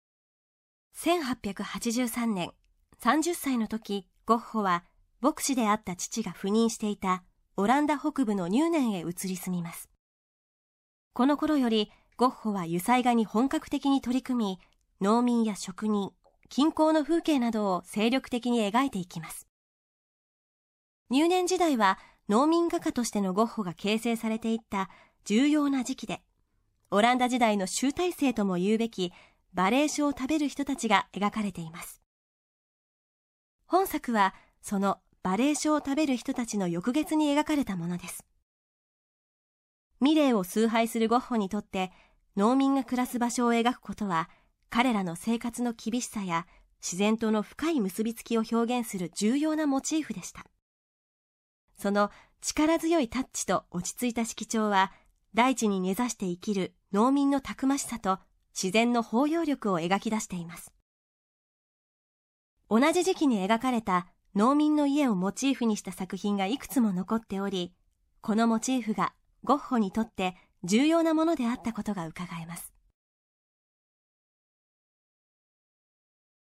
作品詳細の音声ガイドは、すべて東京富士美術館の公式ナビゲーターである、本名陽子さんに勤めていただいております。本名さんは声優、女優、歌手として幅広く活躍されています。